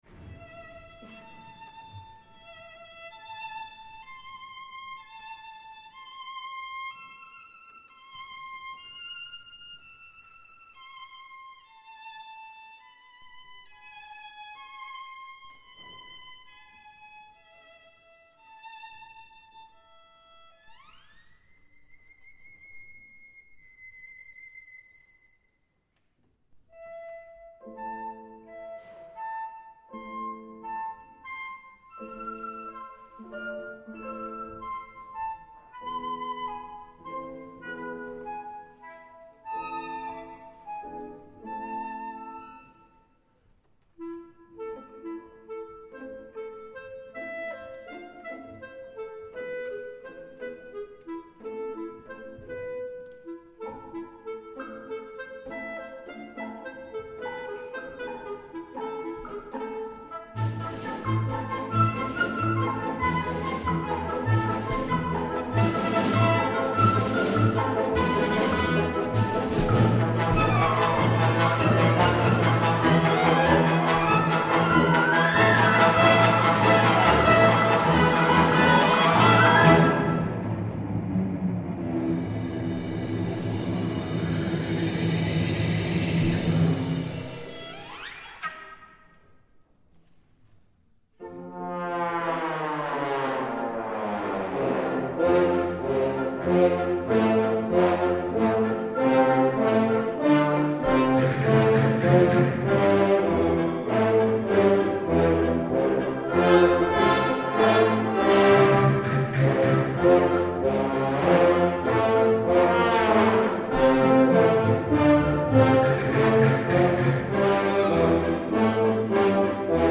оркестровая версия